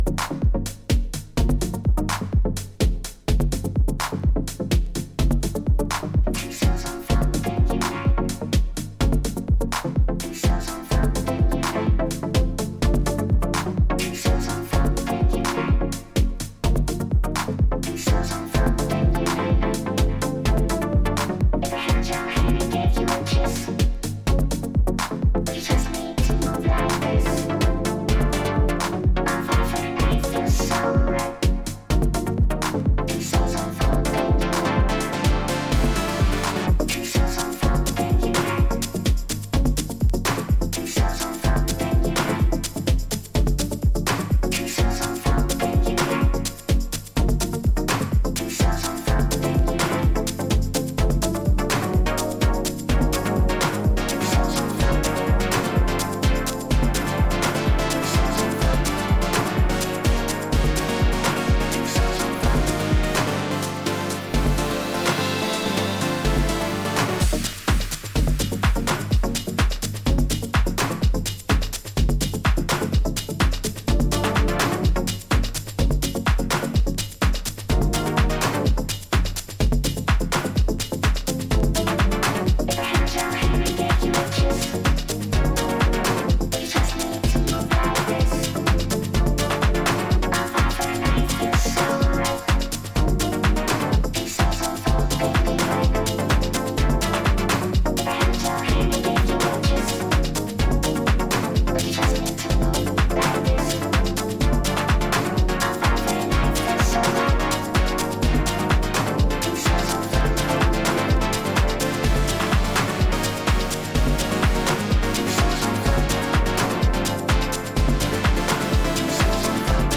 colourful, West Coast US deep house dreaminess